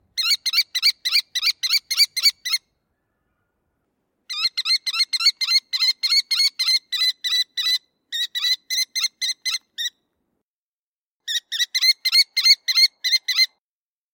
This is a beginner-level comparison group of rattle-like bird songs and sounds from Land Birds of North America, Eastern/Central region.
American Kestrel - Call
High, rapid kily-kily-kily-kily.